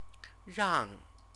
chinese_characters_rang_rang.mp3